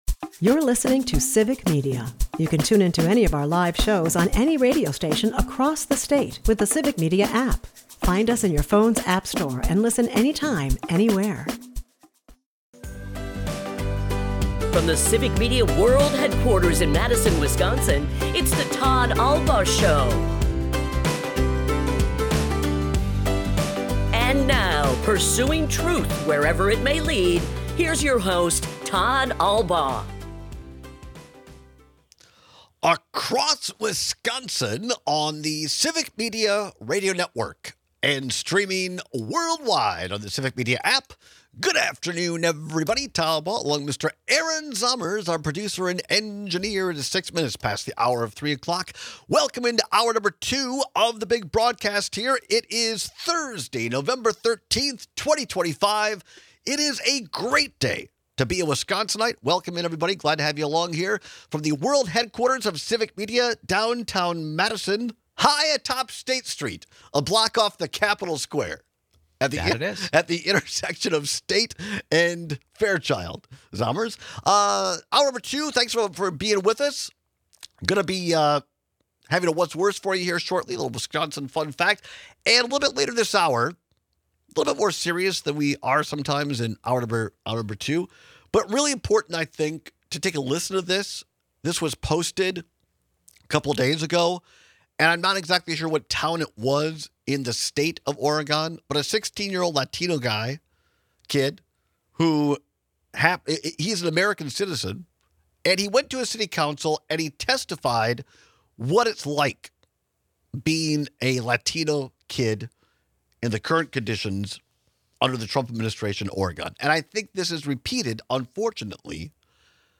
We take your calls and texts and talk about how ADHD affects our perception of time.